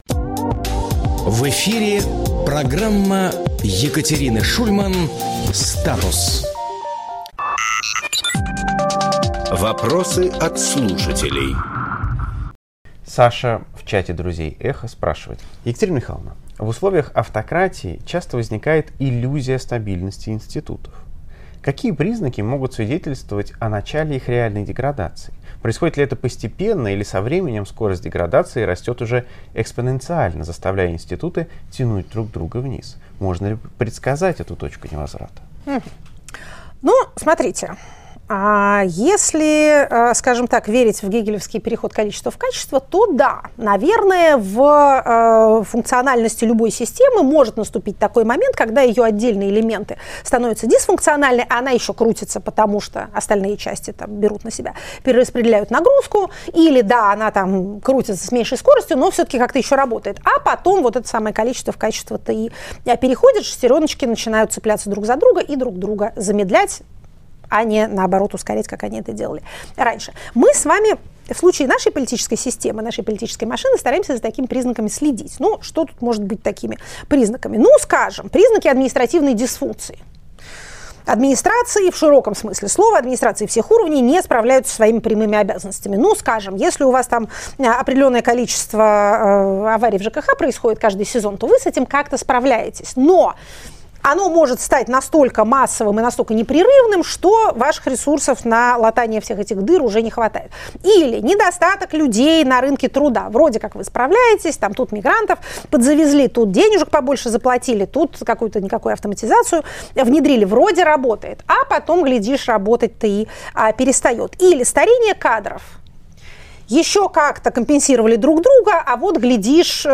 Екатерина Шульманполитолог
Фрагмент эфира от 04.03